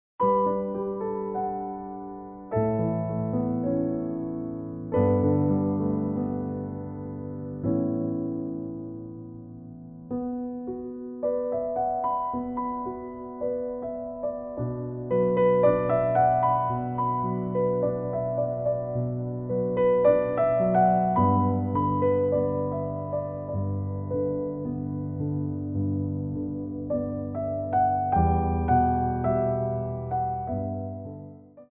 Port de Bras 2
4/4 (8x8)